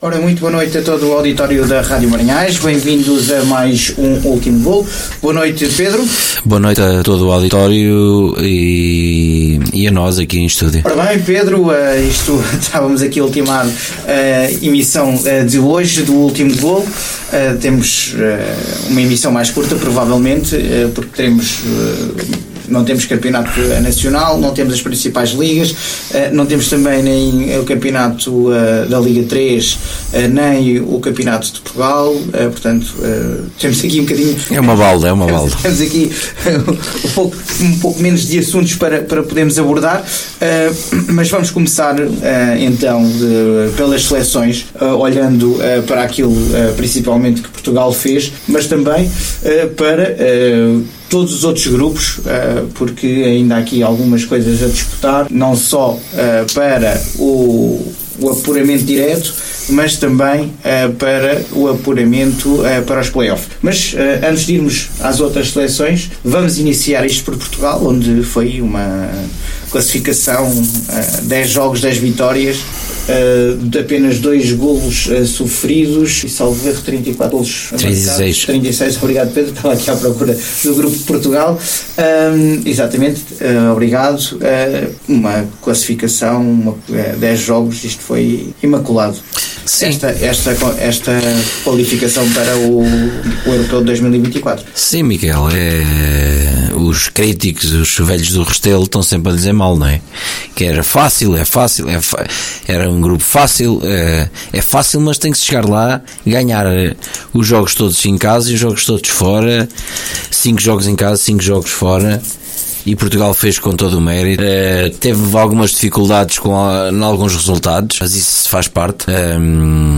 O Último Golo é o programa desportivo da Rádio Marinhais que conta com um painel diversificado de comentadores que, semana após semana, analisam as várias competições de futebol a nível regional, nacional e internacional.
Este programa é emitido, em direto, às segundas-feiras, das 21h às 23h, ou, excecionalmente, à terça-feira, no mesmo horário.